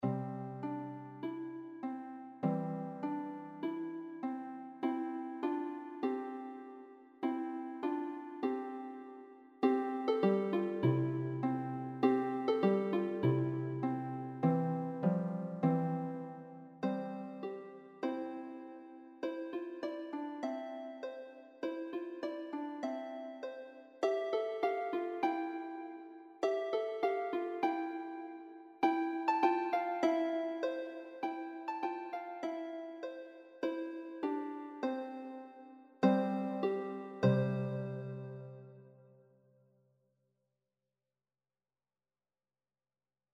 is for solo lever or pedal harp